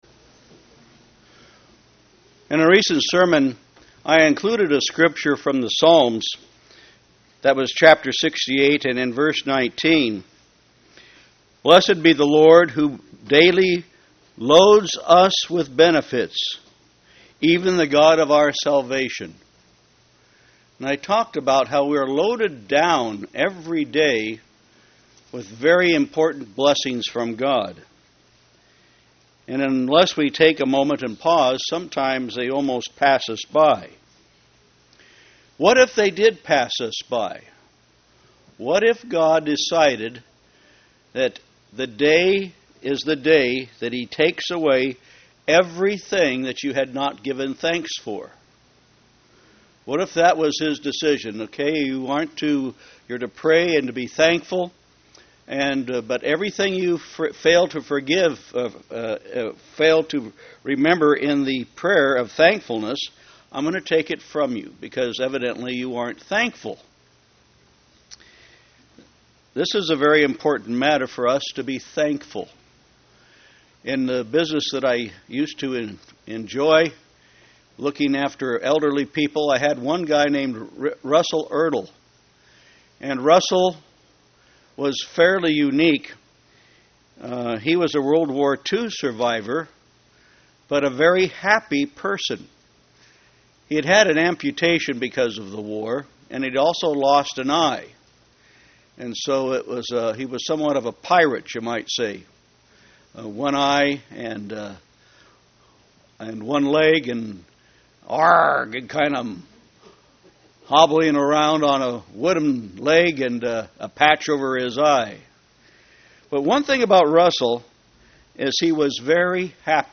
Given in St. Petersburg, FL
Print Four points of 1 Thessalonians 5:18 UCG Sermon Studying the bible?